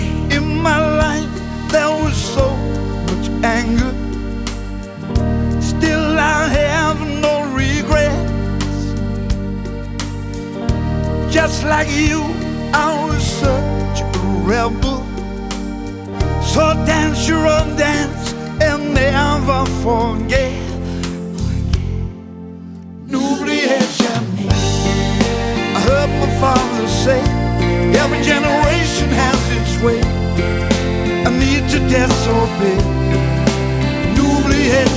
# Adult Contemporary